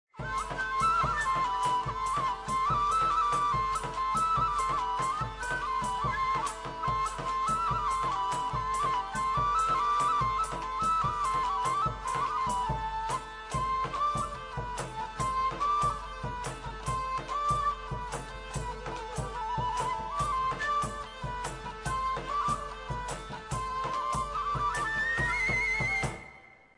L'immagine mostra una viella; l'audio, un frammento di virelai, una forma musicale molto in uso in questo periodo
virelai.mp3